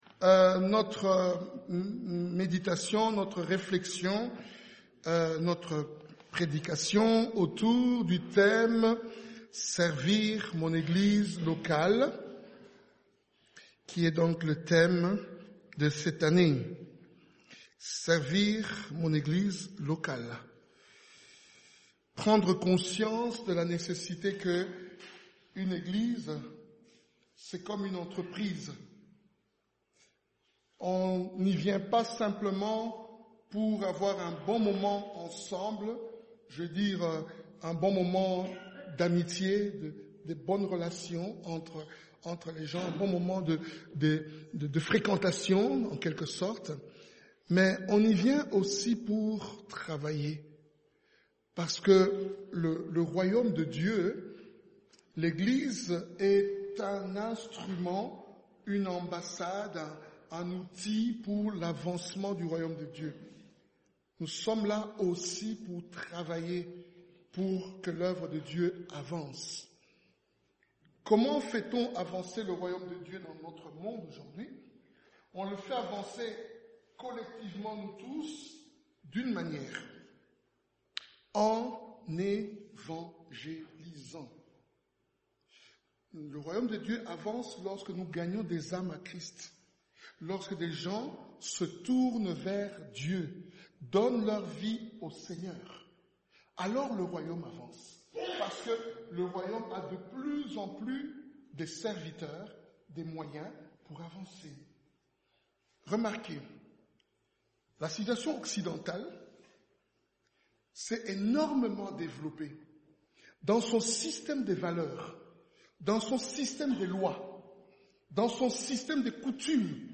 Type De Service: Dimanche matin